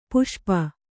♪ puṣpa